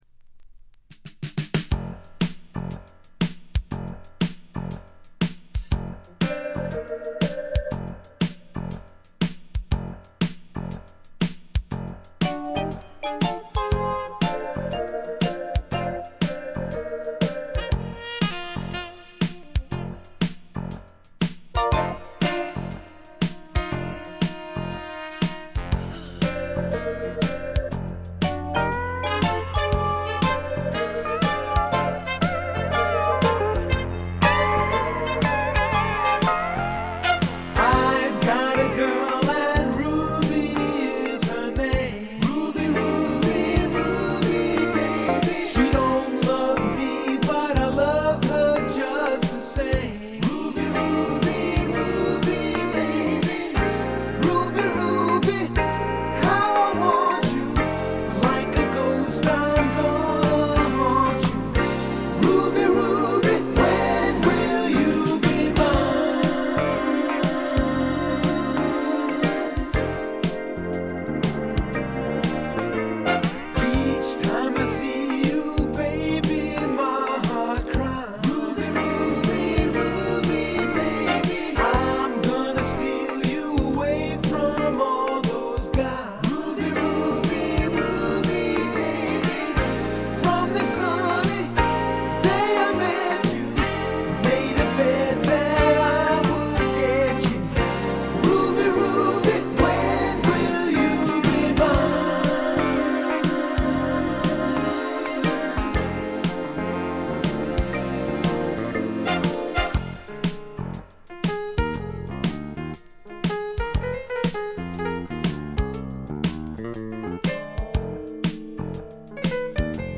チリプチ少々（特にA面曲が始まる前の無音部）。
試聴曲は現品からの取り込み音源です。